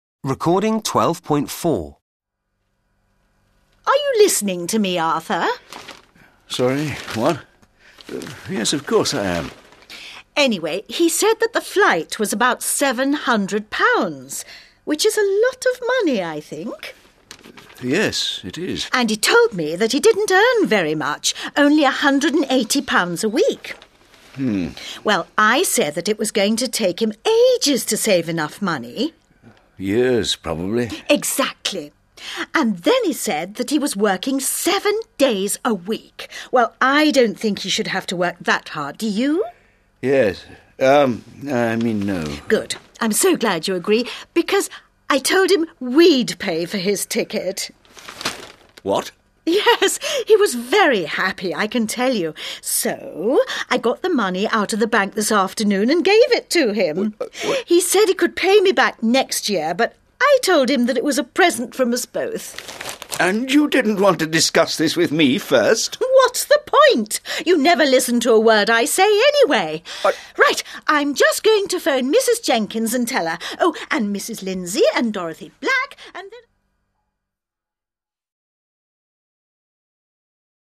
conversation_C.mp3